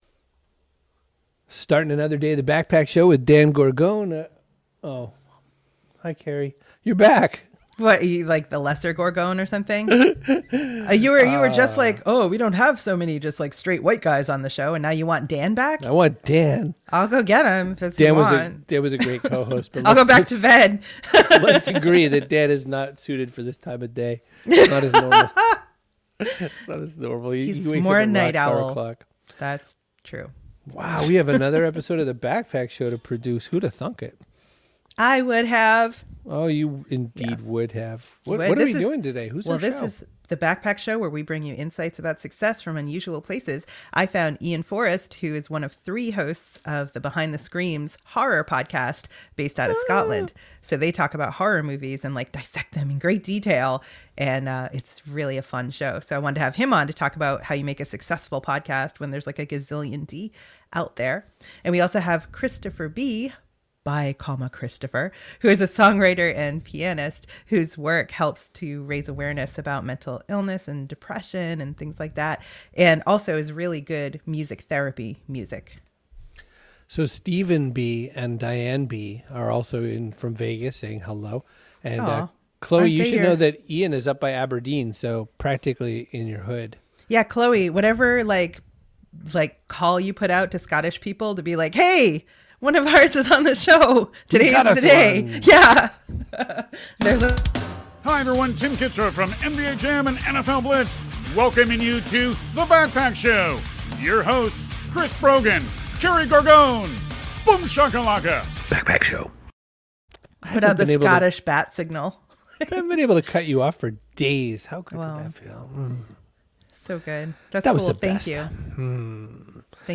Come for the accent, stay to deconstruct the lasting appeal of horror films!